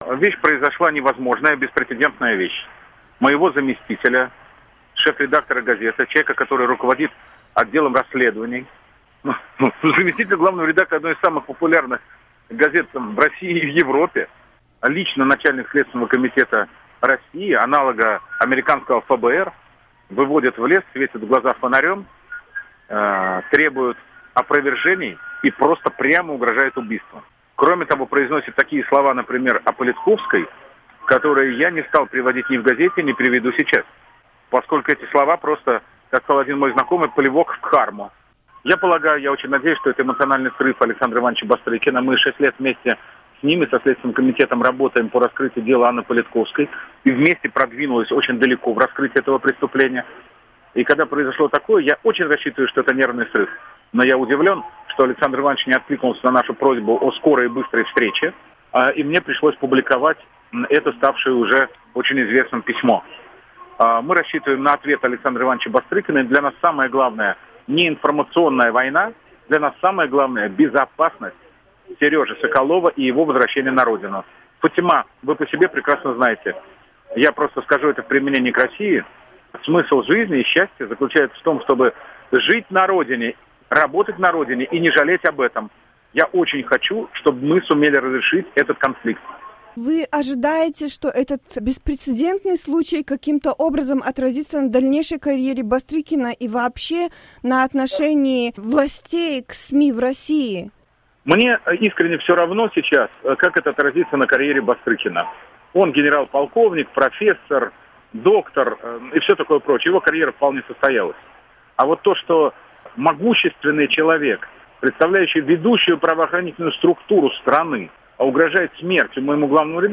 Интервью с Дмитрием Муратовым